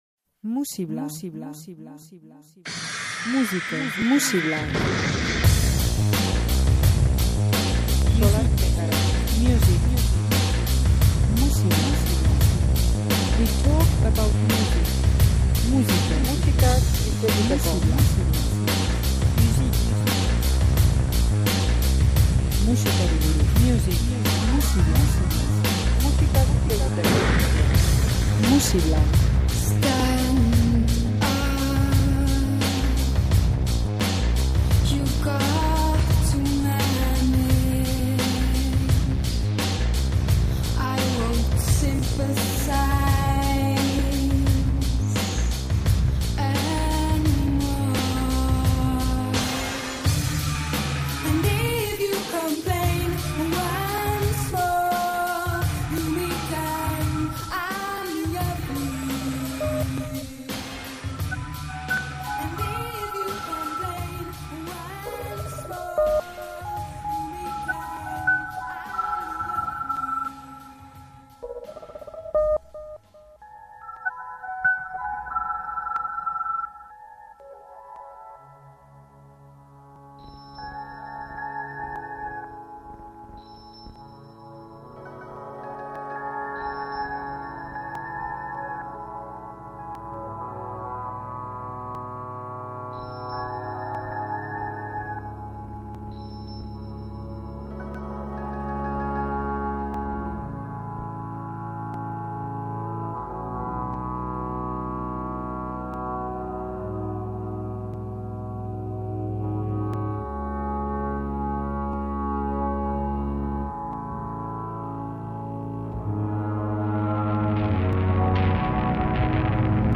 instrumentu primitiboak eta erritmo erakargarriak nahasiz.